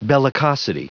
Prononciation du mot : bellicosity